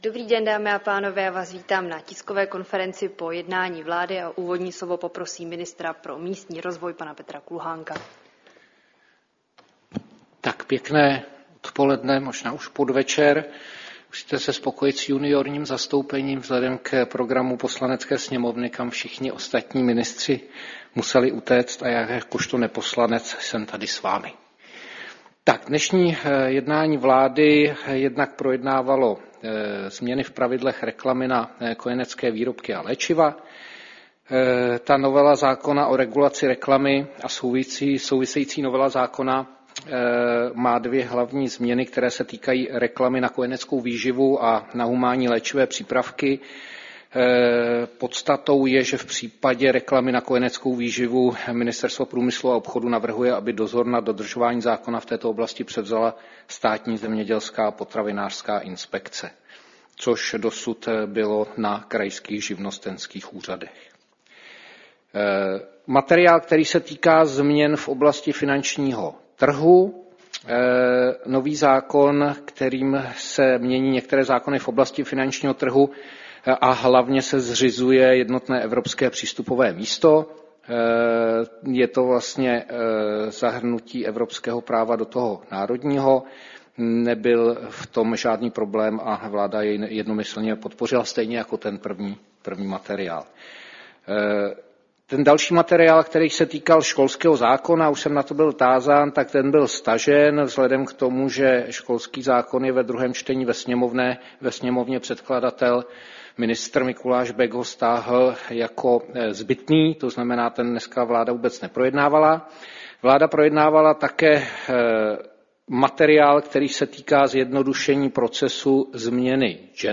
Tisková konference po jednání vlády, 12. února 2025